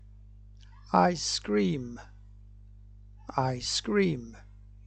(eye-scream)
eye-scream.mp3